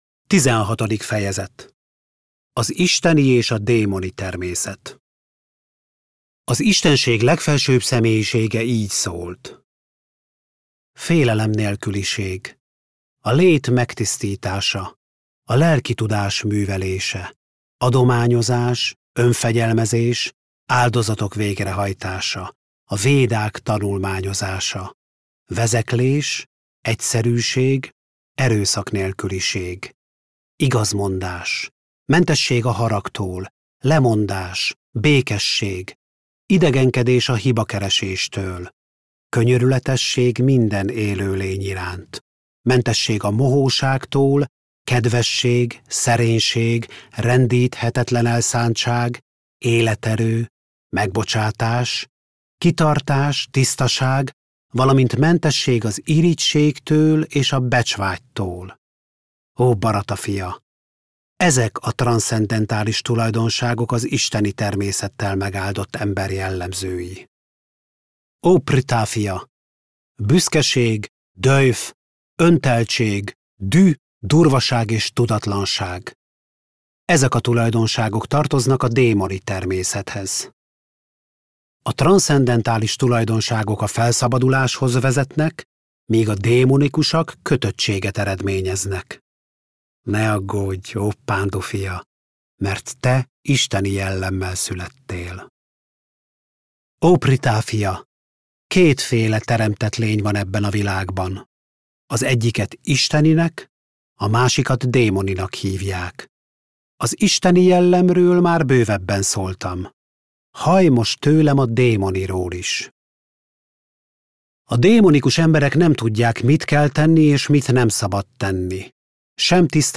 Bhagavad-gītā hangoskönyv – Tizenhatodik fejezet - Magyarországi Krisna-tudatú Hívők Közössége